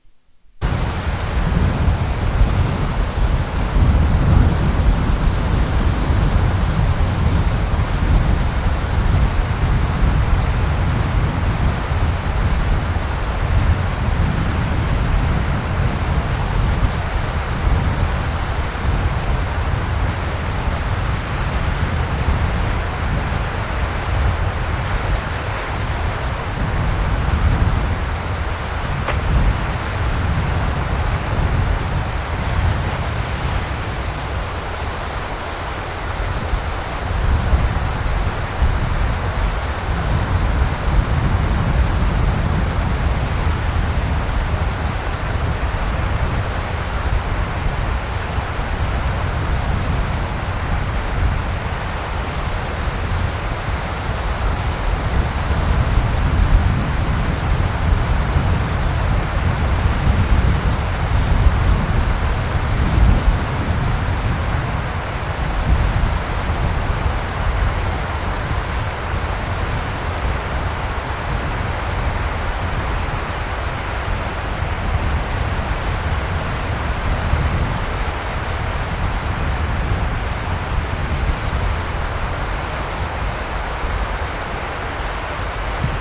温和的波浪从左到右 " 温和的波浪从左到右剥落 1
描述：一个美丽的、平静的、在我当地海滩上的清晨。2英寸的海浪从左到右轻轻地剥落，三脚架上的录音机在水洗中弄湿了脚。
标签： 海滩 波浪 温柔 现场录音 立体声
声道立体声